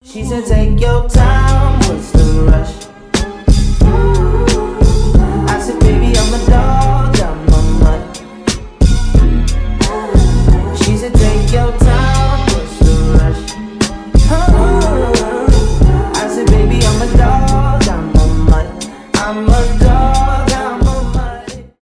соул , поп , зарубежные , rnb , хип-хоп